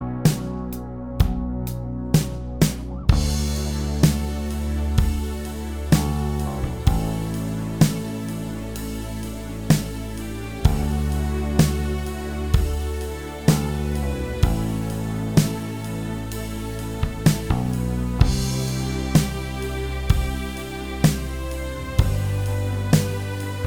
Medley Rock 5:18 Buy £1.50